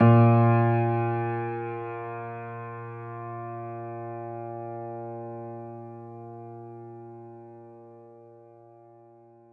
Vintage_Upright